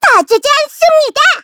Taily-Vox_Skill5_kr_b.wav